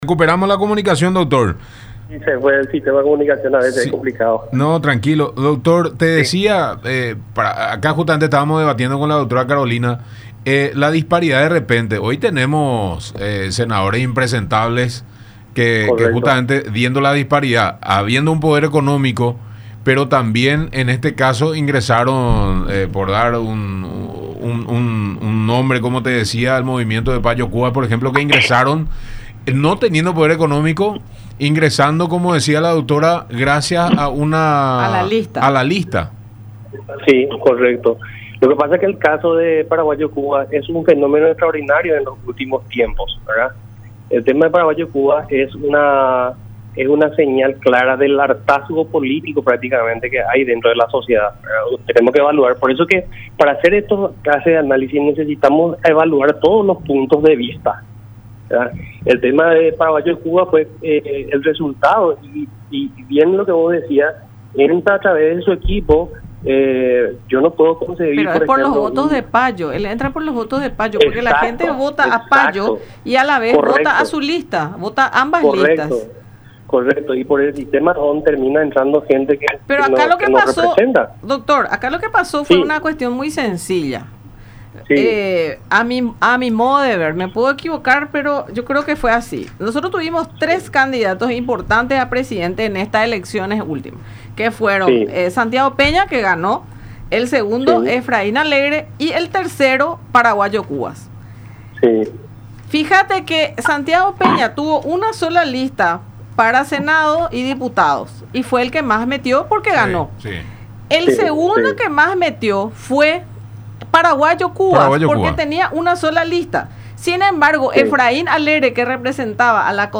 “Los empresarios entran a la política para cuidar sus negocios, no para ser una ayuda a la comunidad”, dijo en el programa “La Mañana De Unión” por Unión Tv y Radio La Unión.